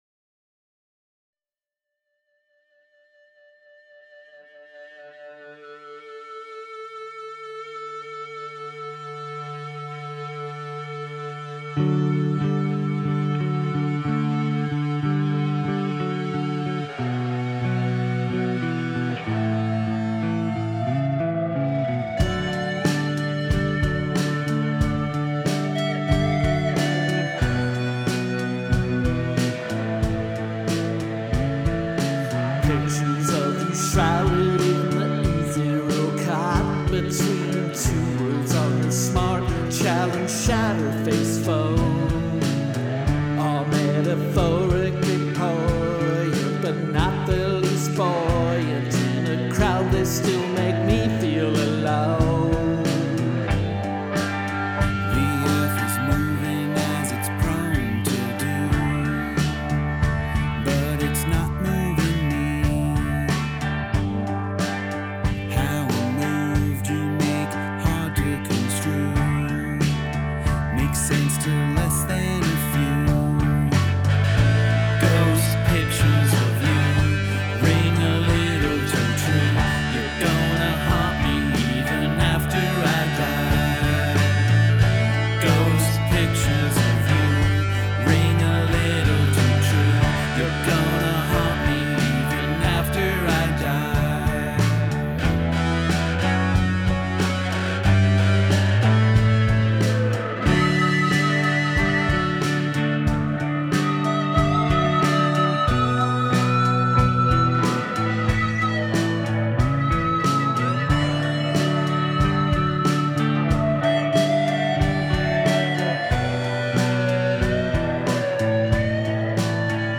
These are our demos — we recorded it all ourselves at home.